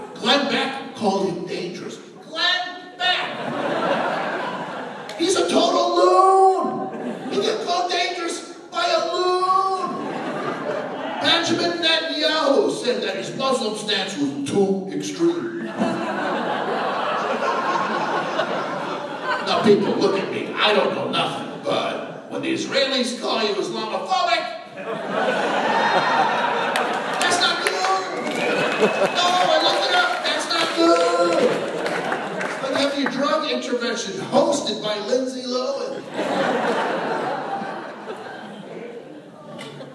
As an example, we went to a performance in Arcata, just outside Humbolt State by Will Durst, a presidential election satirist.